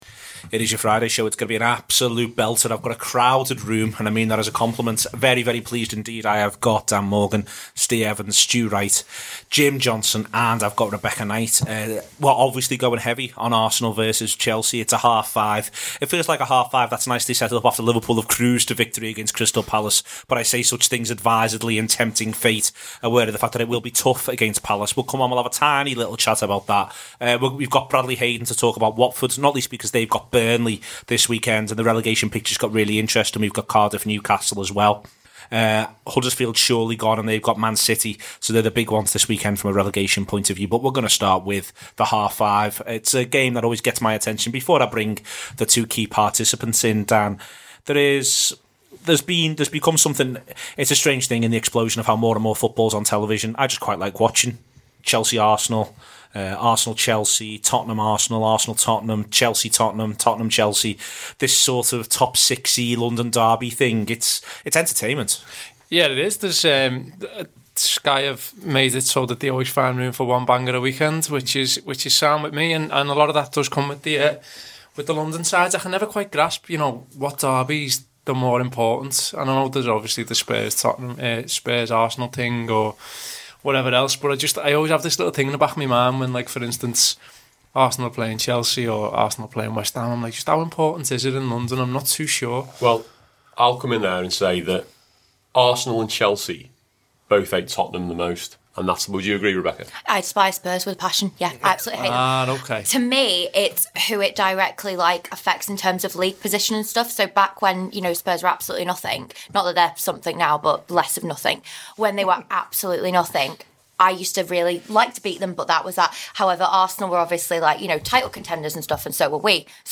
on the phone to talk about Watford